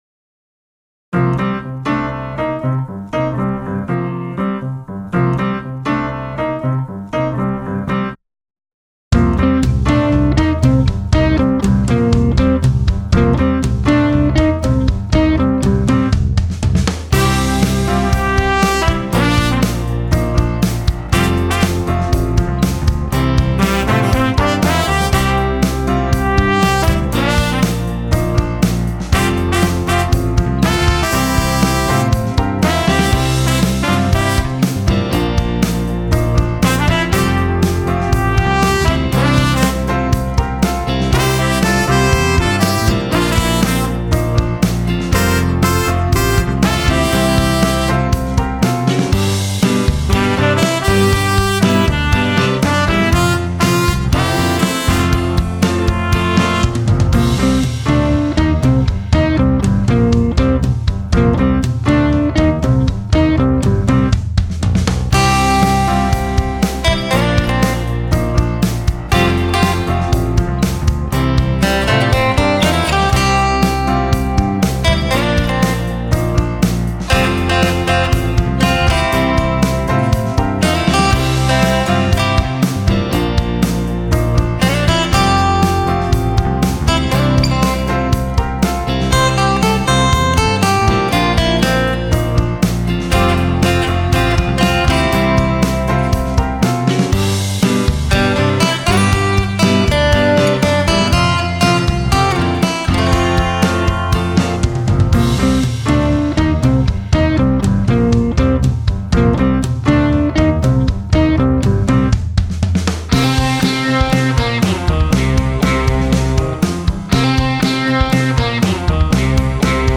something somewhat heavier with guitar harmonies